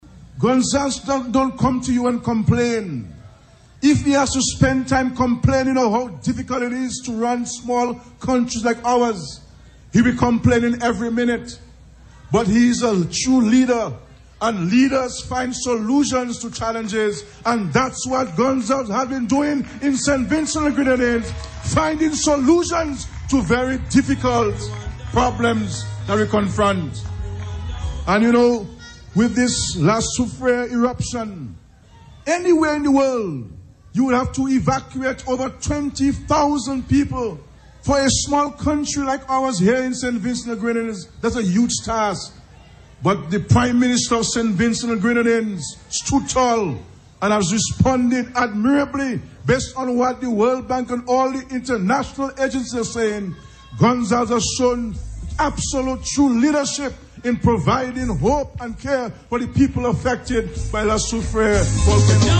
There was a festive atmosphere at the decommissioned E.T. Joshua Airport Sunday, as members and supporters of the Unity Labour Party gathered for a massive Rally to celebrate the Party’s 22nd Anniversary in Government.
Prime Minister of Dominica, Roosevelt Skerrit was among those celebrating with the gathering Sunday.
SKERRIT-RALLY.mp3